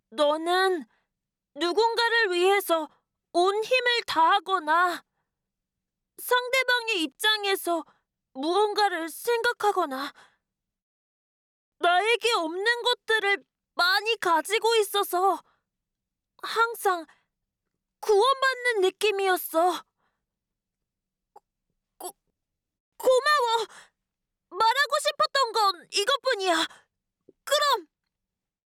幼い男の子